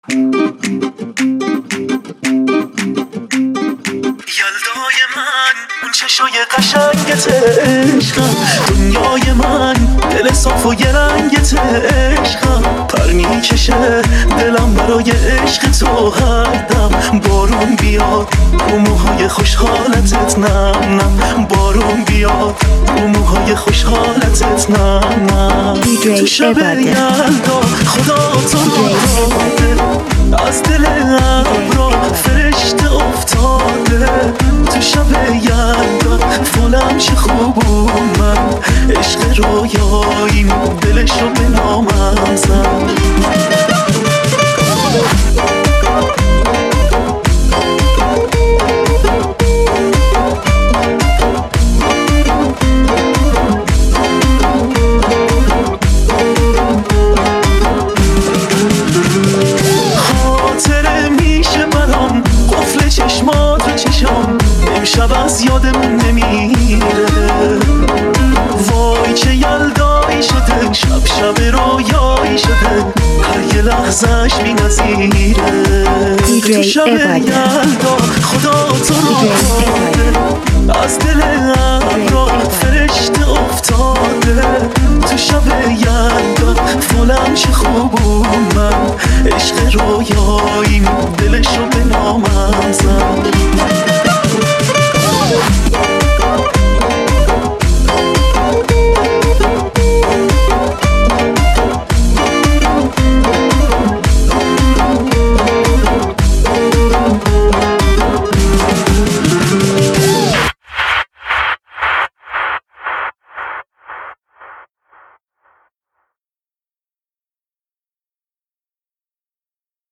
ریمیکس شاد